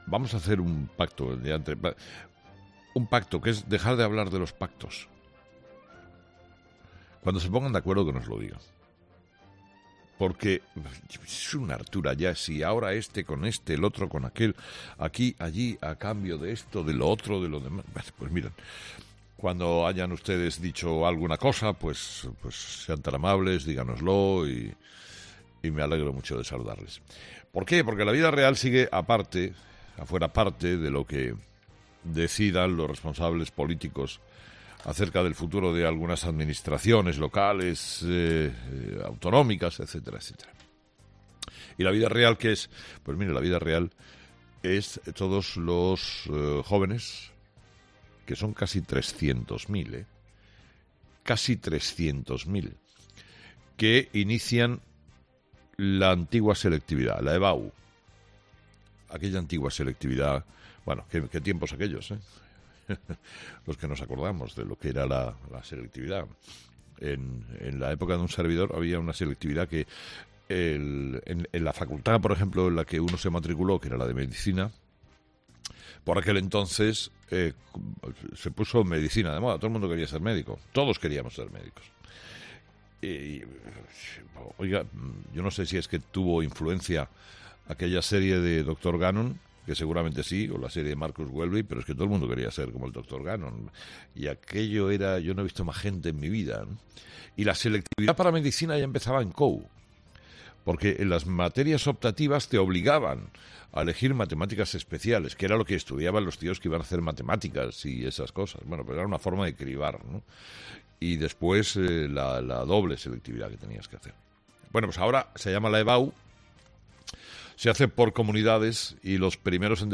Carlos Herrera ha comenzado su monólogo de las seis de la mañana de este lunes 3 de junio proponiendo lo siguiente a los oyentes: “Vamos a hacer un pacto y no vamos a hablar de los pactos.